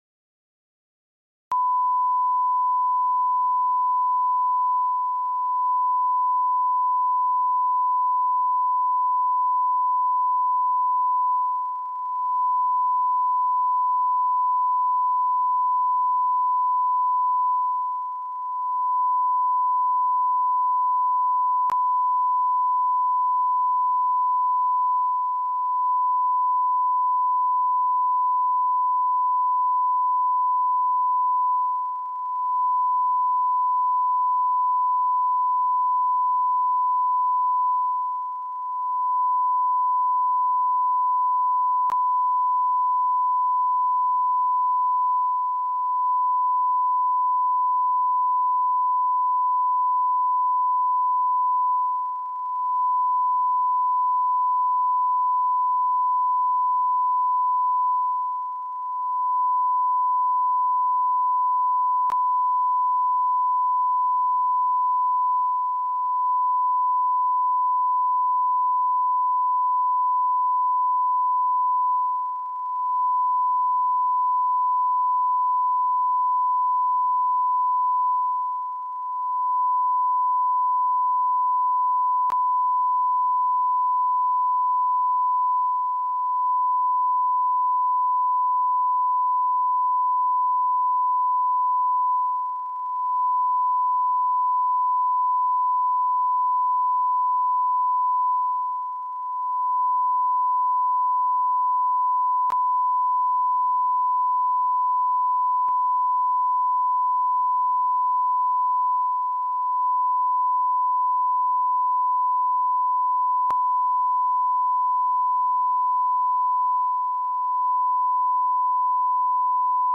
Annoying Beep Noise.mp3